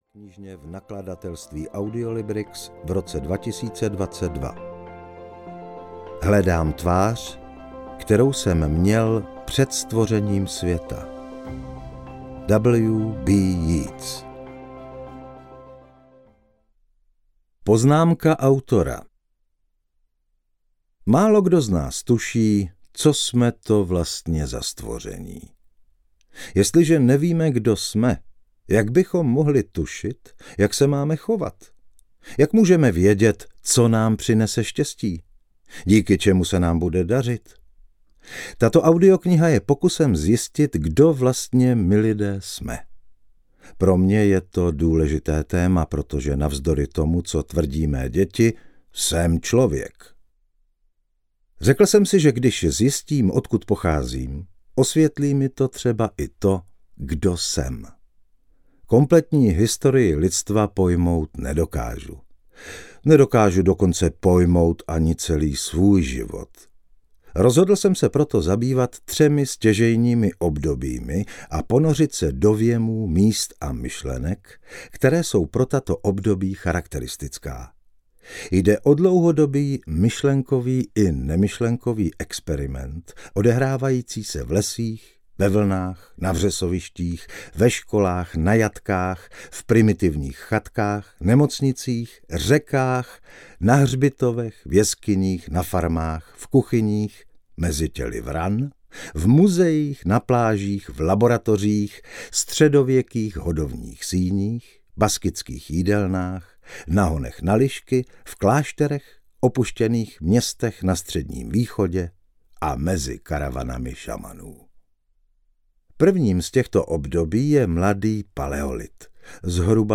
Jako člověk audiokniha
Ukázka z knihy
jako-clovek-audiokniha